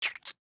salamander-v3.ogg